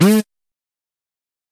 interupted.ogg